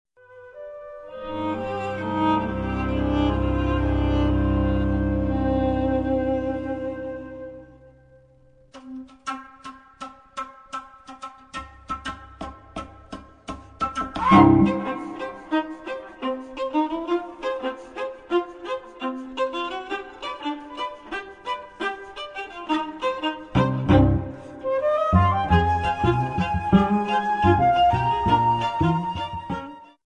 Flauto traverso e shaker
Violino
viola
violoncello